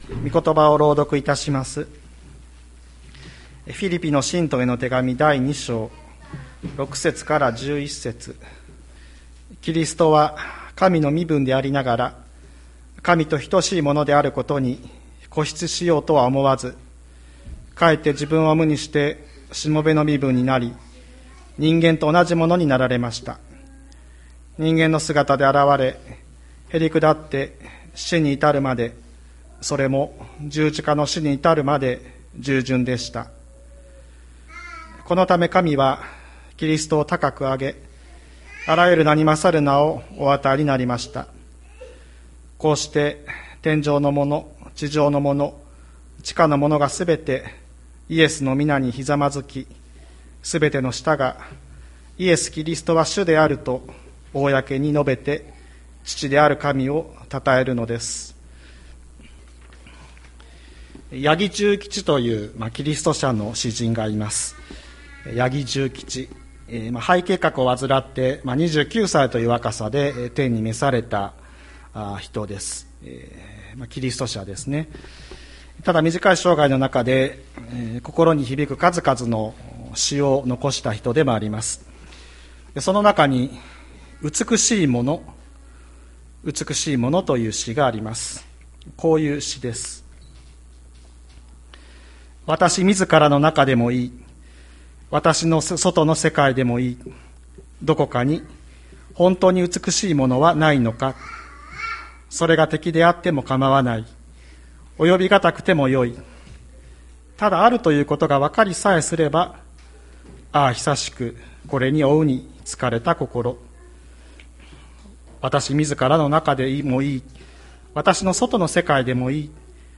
千里山教会 2022年06月19日の礼拝メッセージ。